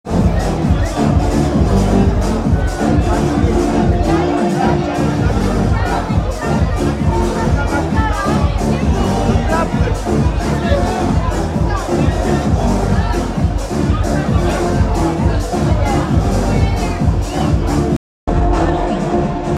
w Muzyka elektroniczna
Odsluch kiepski.